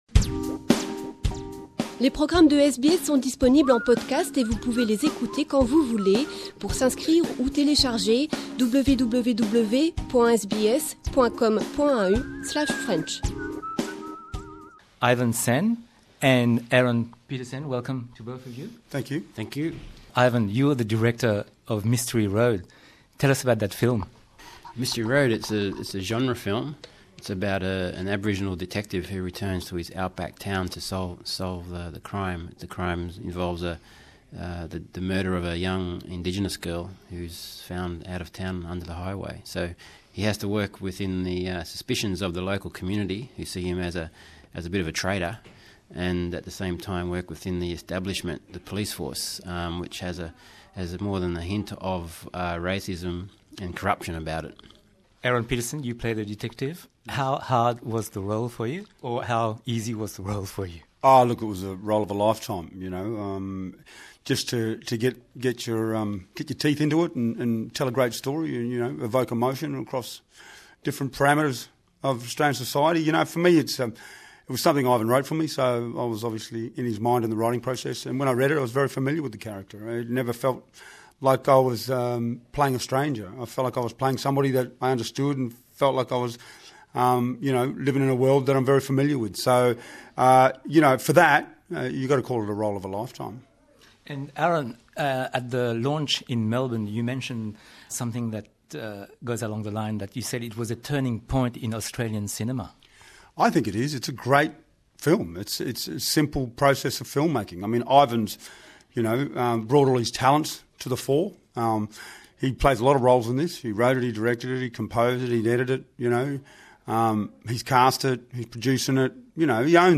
Pour marquer NAIDOC Week, on vous propose la rediffusion depuis nos archives, cette interview avec le réalisateur Ivan Sen et l’acteur Aaron Pedersen pour le film Mystery Road. Ils sont venus nous présenter leur film sur SBS French, il y a quelque temps.